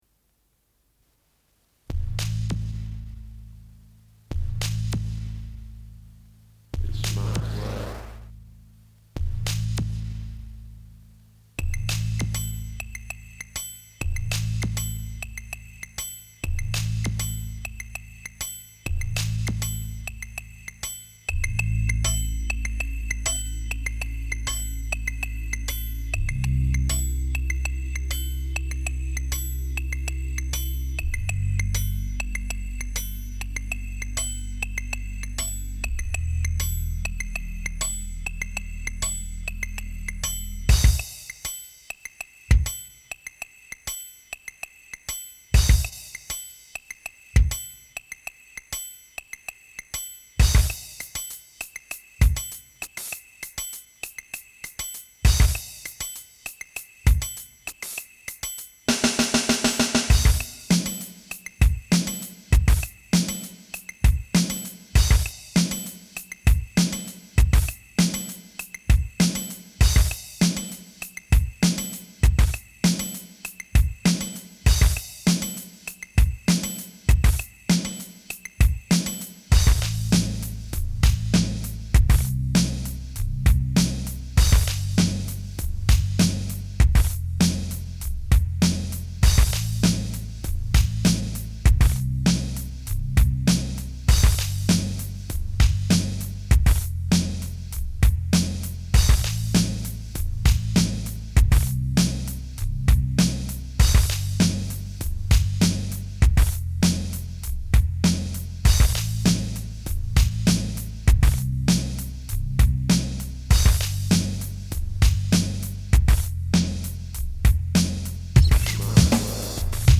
Mostly MIDI.